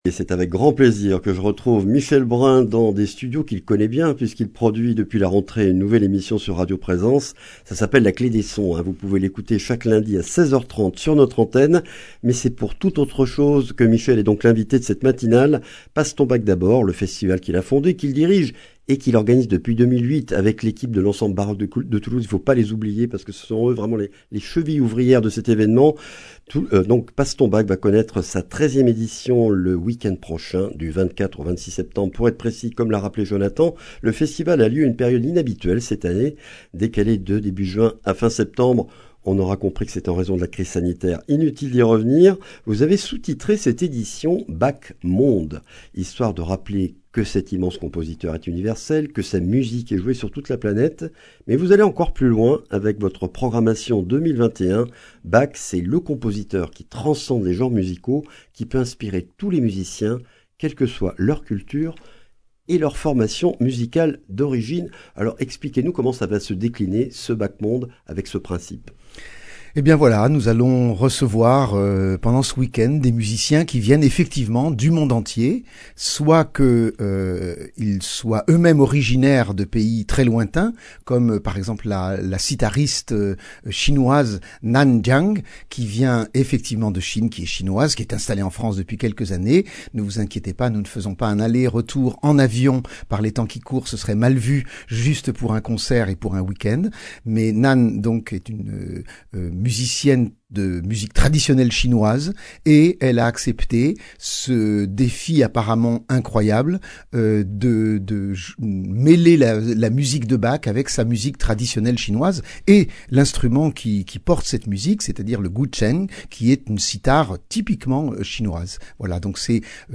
Accueil \ Emissions \ Information \ Régionale \ Le grand entretien \ Passe ton Bach d’abord, 13e édition du 24 au 26 sept.